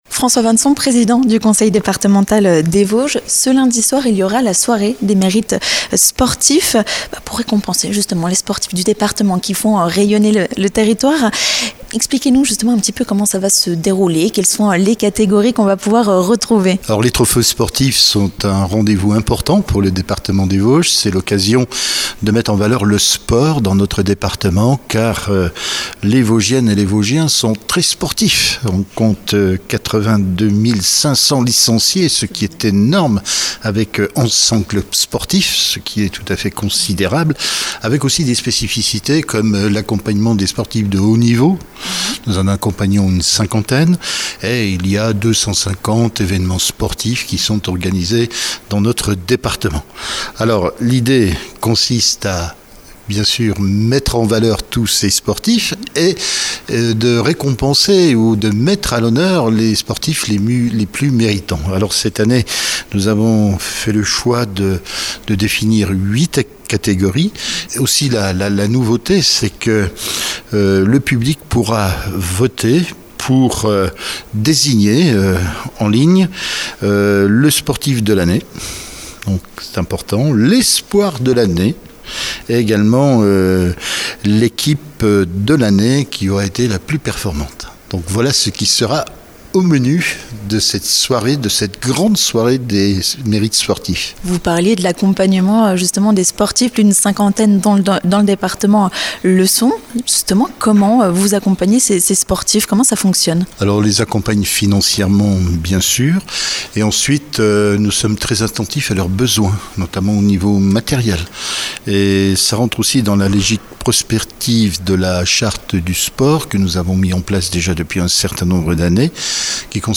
Le président du Conseil départemental des Vosges, François Vannson, revient pour nous sur cette cérémonie et sur la politique sport menée dans le département.